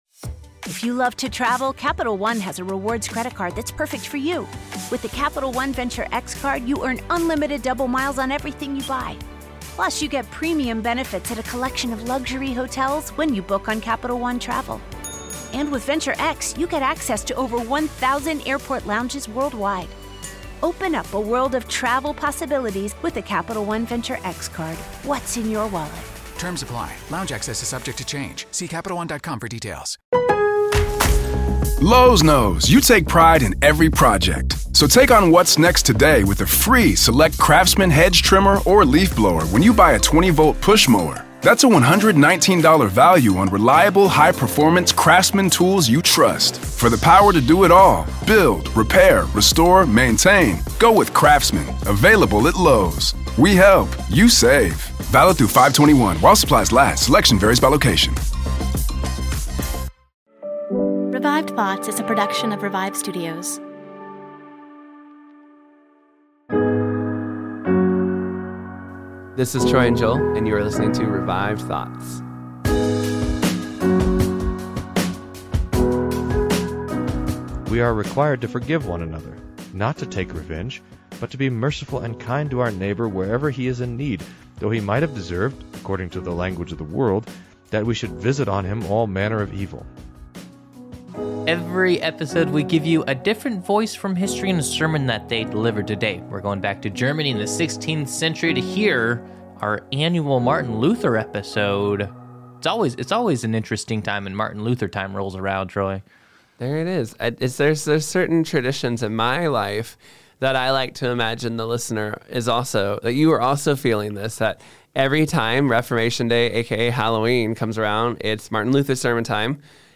Every year Revived Thoughts puts out a Martin Luther sermon in honor of Reformation Day!